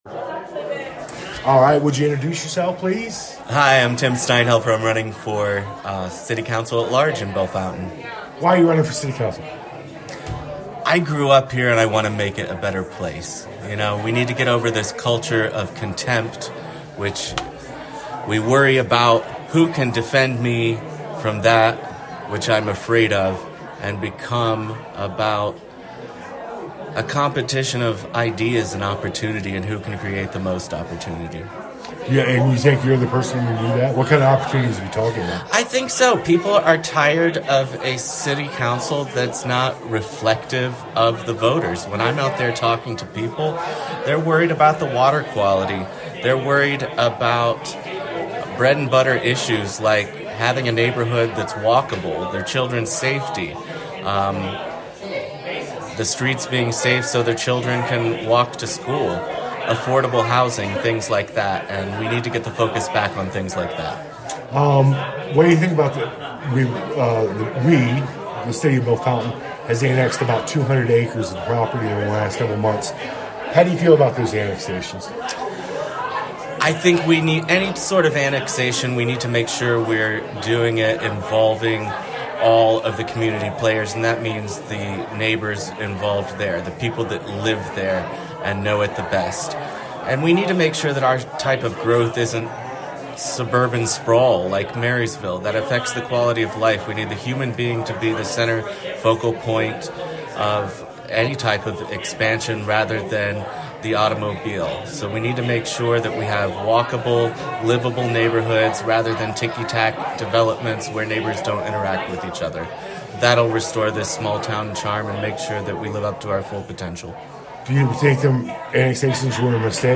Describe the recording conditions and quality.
The recordings were slightly edited for clarity, but the responses from the candidates are presented in full.